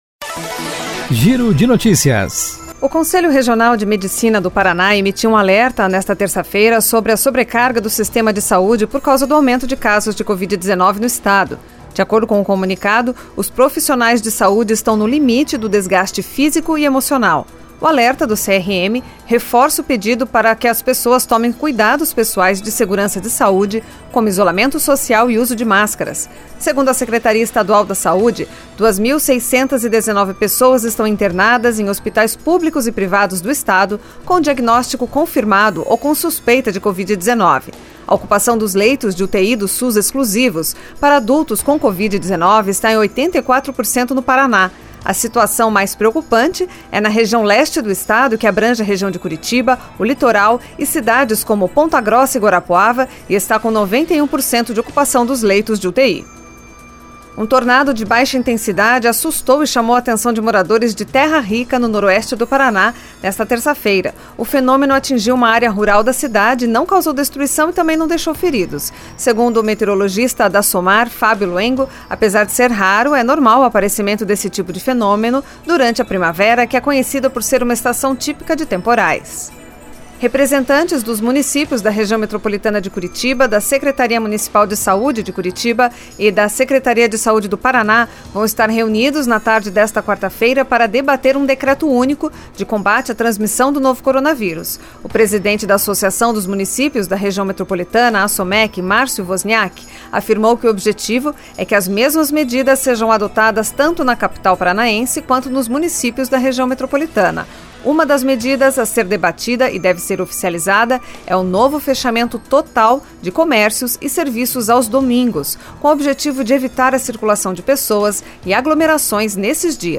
Giro de Notícias Manhã COM TRILHA.